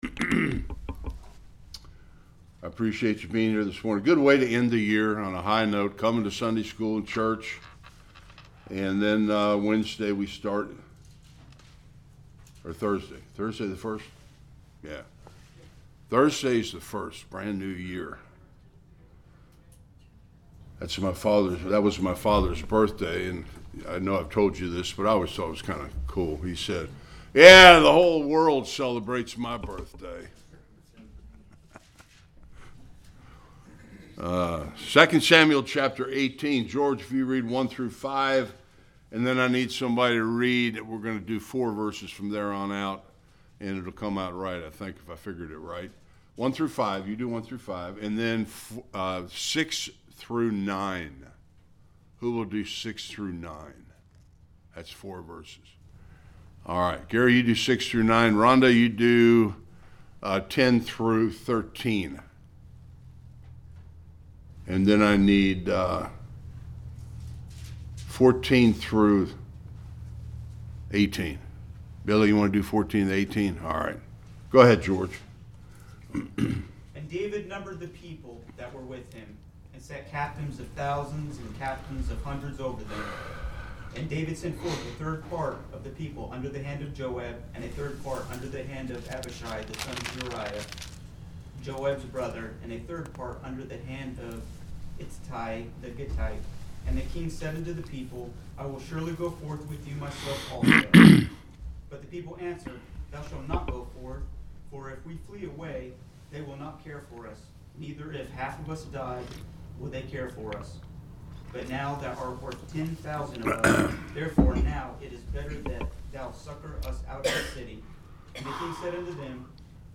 1-33 Service Type: Sunday School David reclaims the throne.